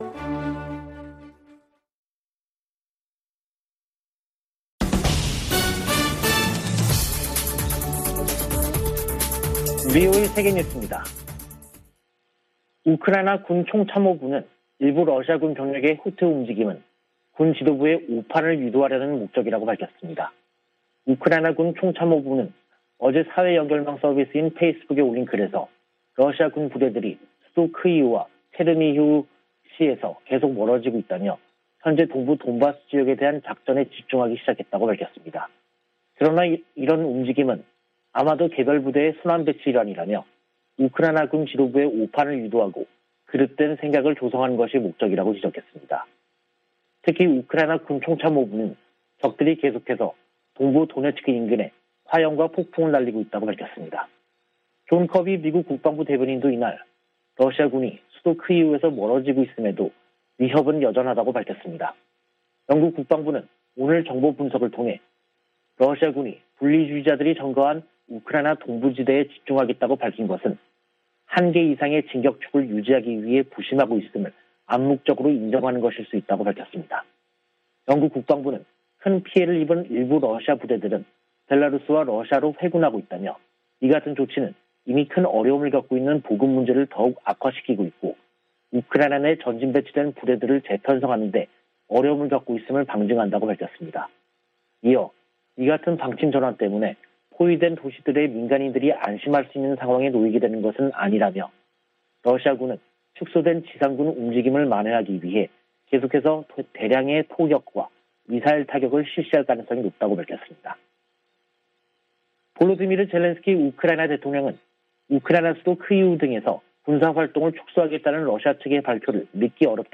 VOA 한국어 간판 뉴스 프로그램 '뉴스 투데이', 2022년 3월 30일 2부 방송입니다. 조 바이든 미국 대통령과 리셴룽 싱가포르 총리가 북한의 잇따른 탄도미사일 발사를 규탄하고 대화로 복귀할 것을 촉구했습니다. 북한의 최근 ICBM 발사는 미사일 역량이 증대 됐음을 보여준다고 미 인도태평양사령부 측이 밝혔습니다. 미 국방부가 북한 등의 위협에 대비하는 내용이 담긴 새 회계연도 예산안을 공개했습니다.